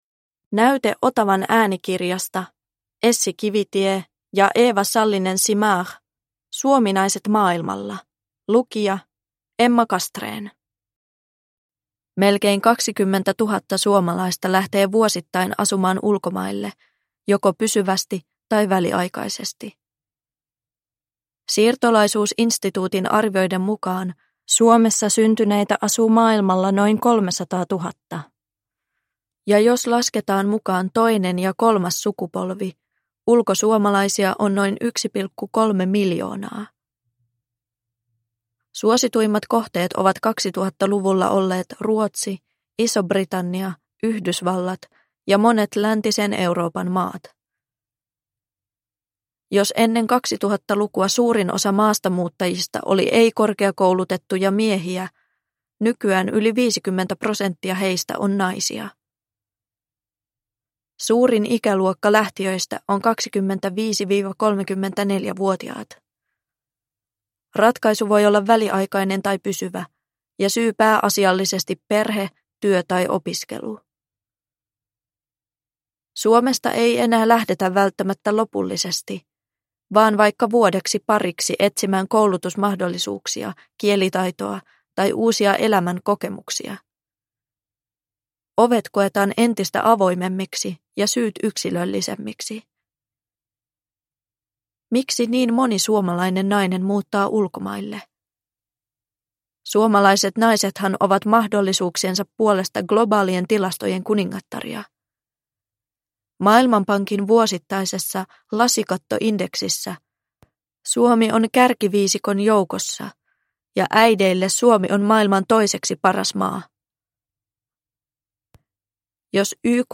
Suominaiset maailmalla – Ljudbok – Laddas ner